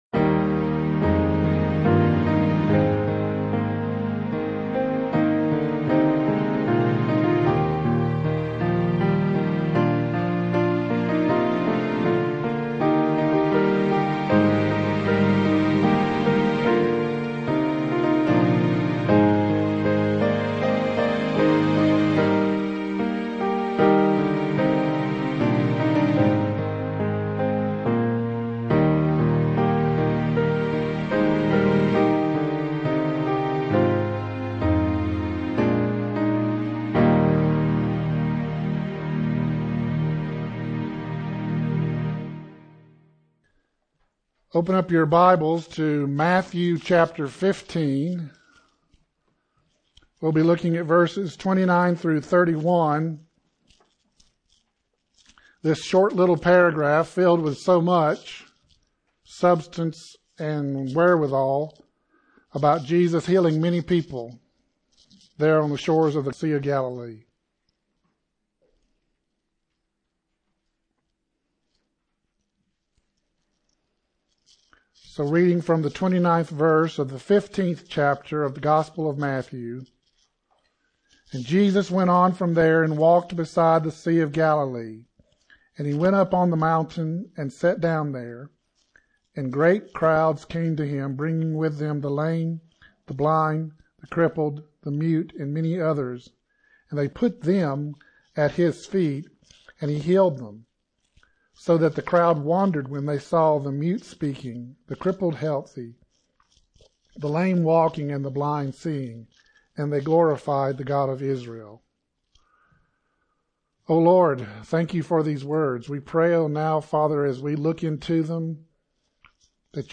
1 Sermon - Jesus Heals Many 33:45